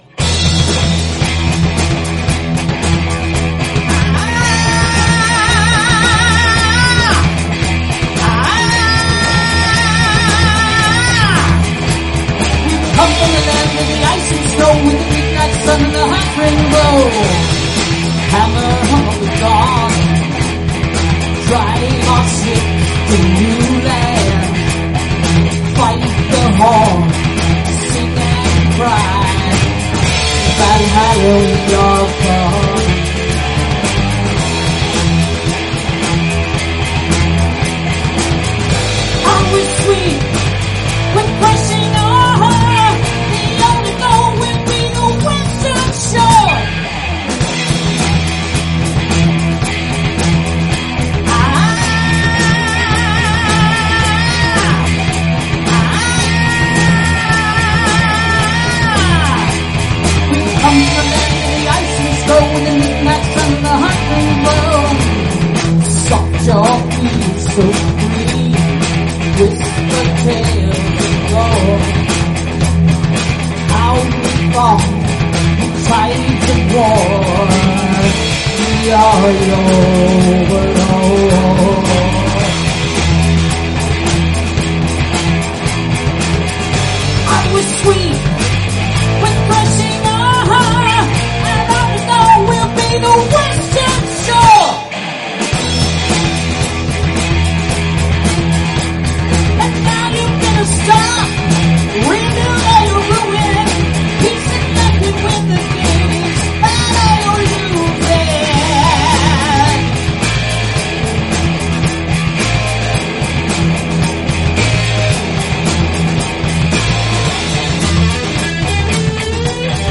Guitar, Keyboards, Vocals
Bass, Vocals
Guitar, Vocals, Keys, Harmonica
Drums, Vocals